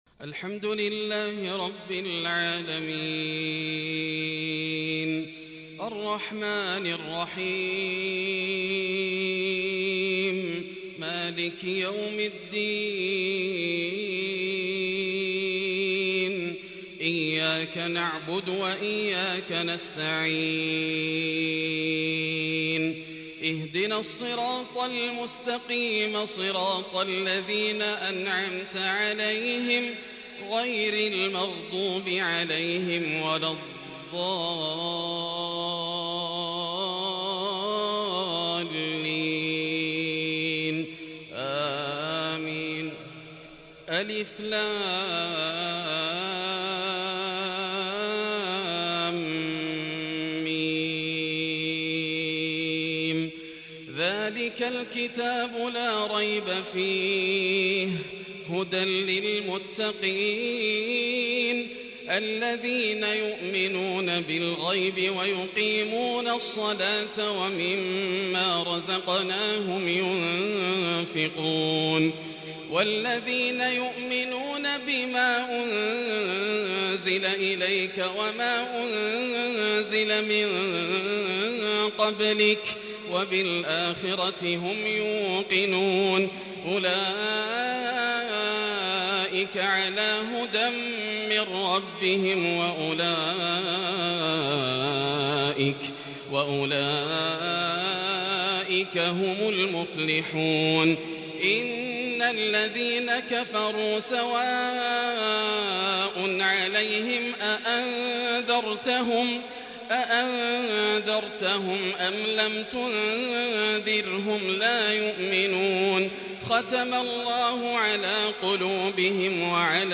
صلاة العشاء تلاوة من سورة البقرة يوم الأحد ٤-١-١٤٤٢هـ .. | Isha prayer From Surat Al-Baqarah 23/8/2020 > 1442 🕋 > الفروض - تلاوات الحرمين